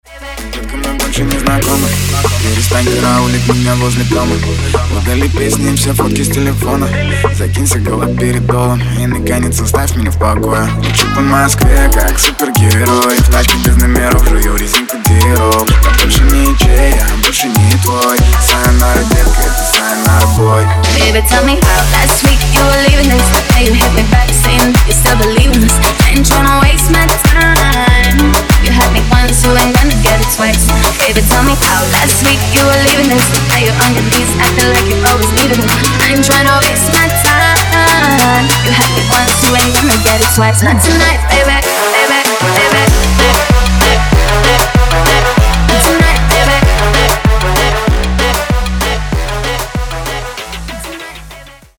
• Качество: 320, Stereo
заводные
дуэт
Moombahton